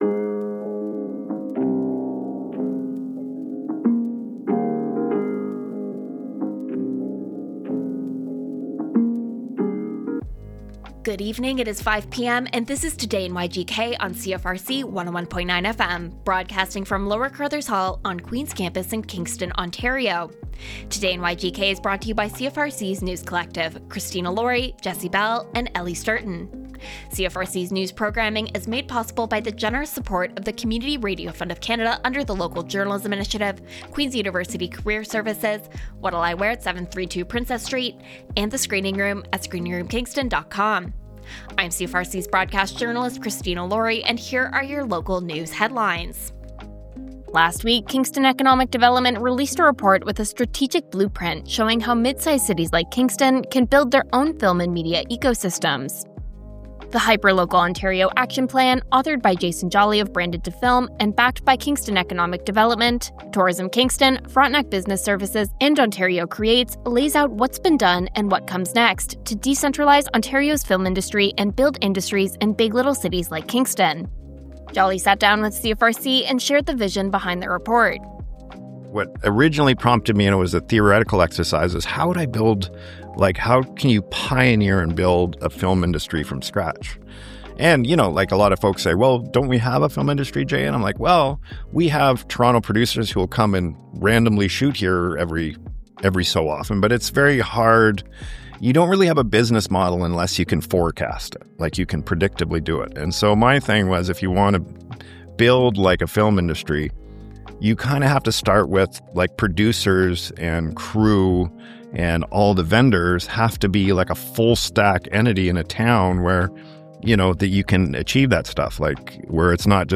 Today in YGK